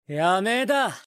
🔻Megumi Voice🔻